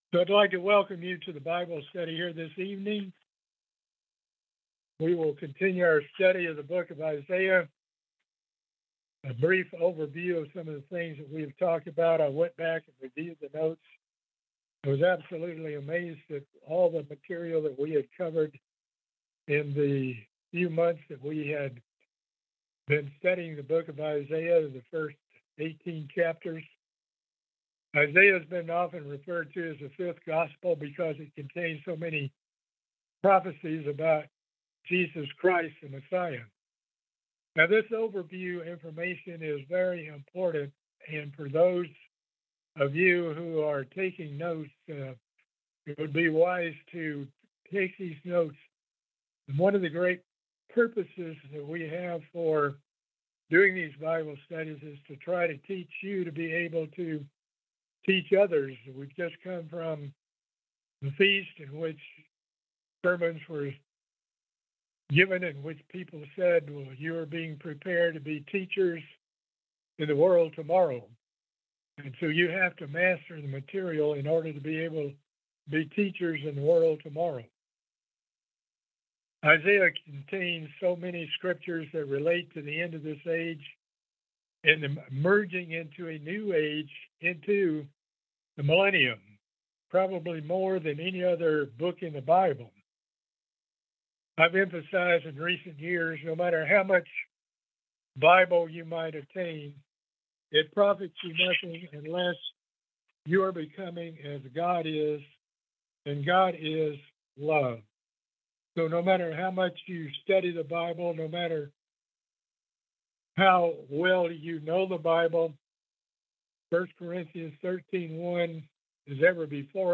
Book of Isaiah Bible Study - Part 15